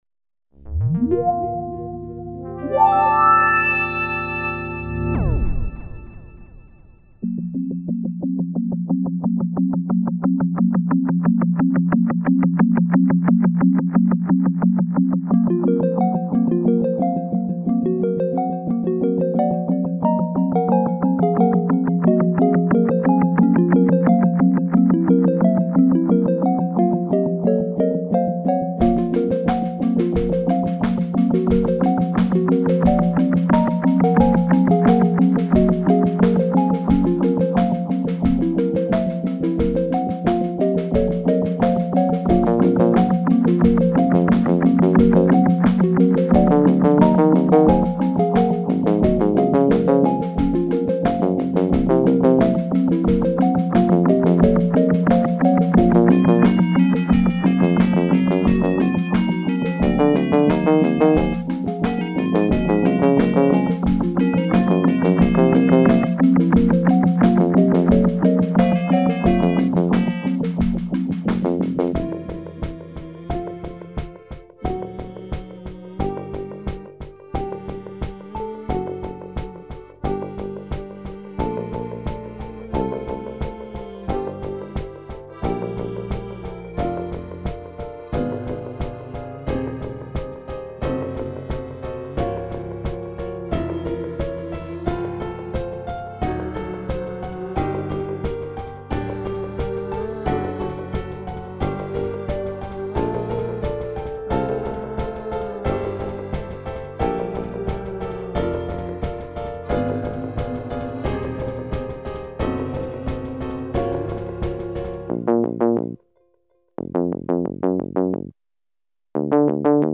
creepy.mp3